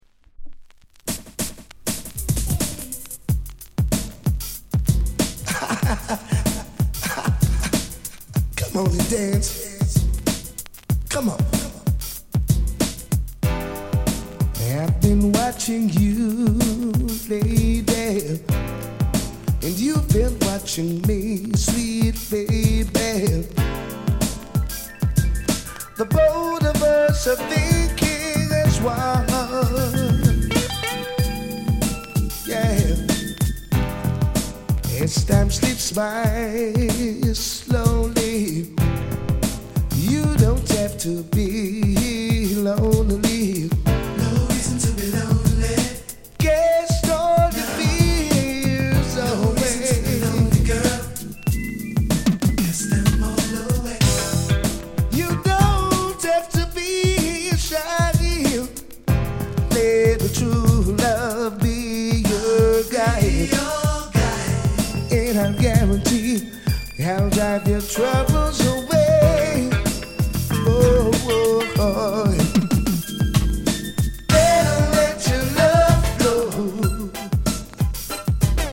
R&B スタイリー！
軽いチリ 乗りますが、気になるレベルではありません。